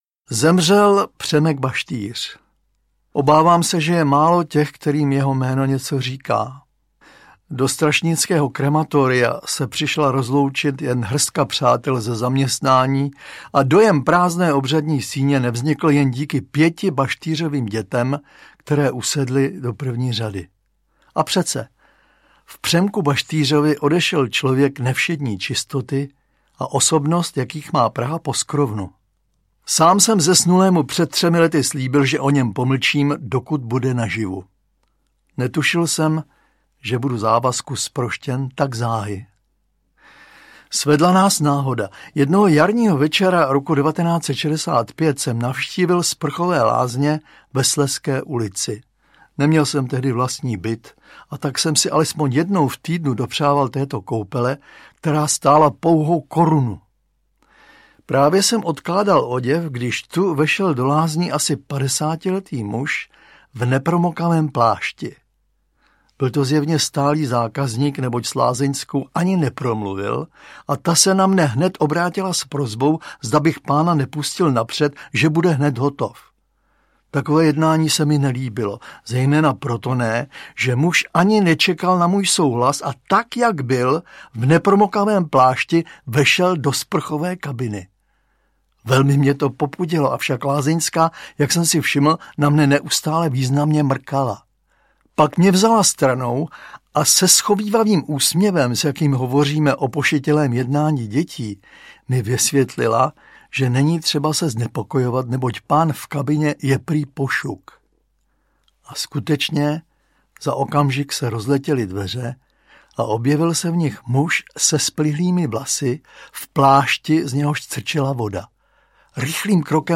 Klasici laskavého humoru audiokniha
Autoři čtou povídky ze svých knih nebo vzpomínají na své herecké kolegy.
Ukázka z knihy